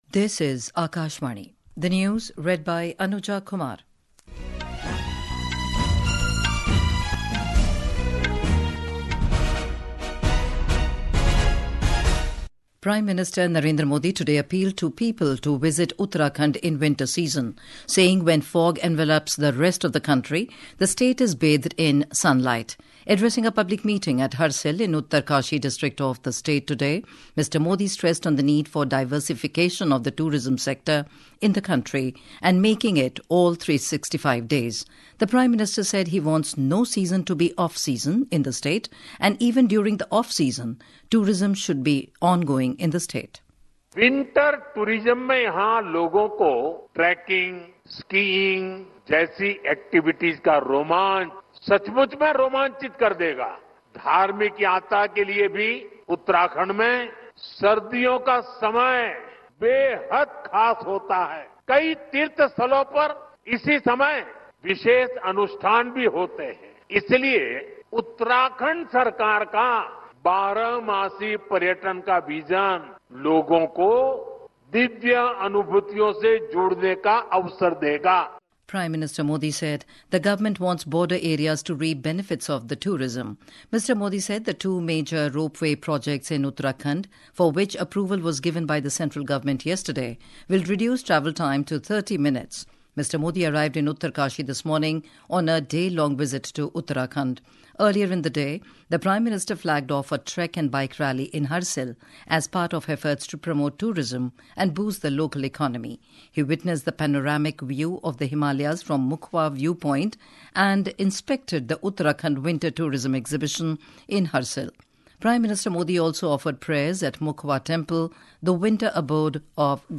Hourly News | English